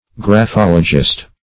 graphologist \graph*ol"o*gist\ (gr[a^]f*[o^]l"[-o]*j[i^]st), n.